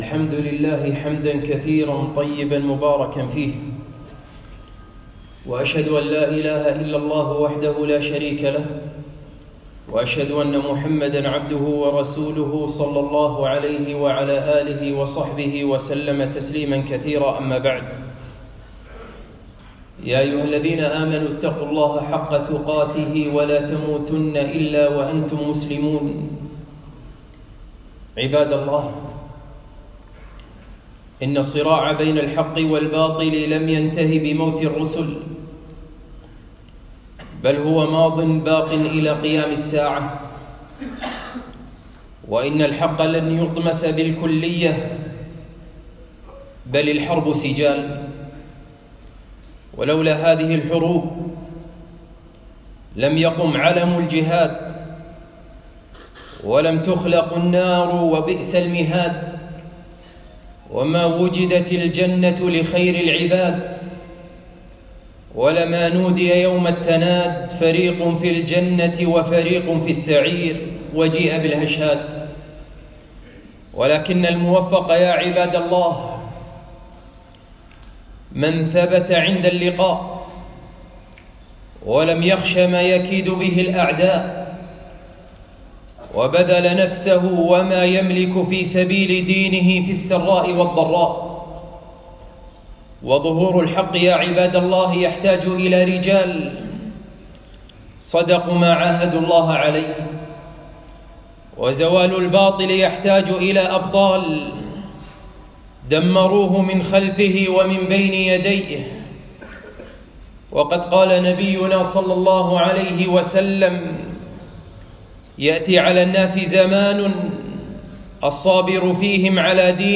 خطبة - الحرب على الحجاب والعفة والحياء